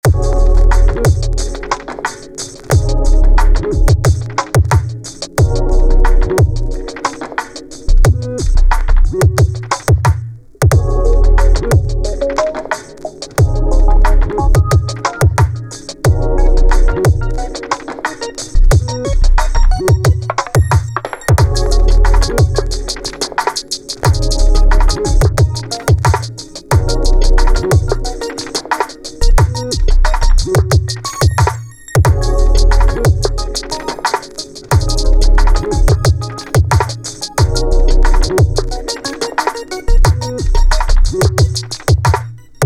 Electric modern loop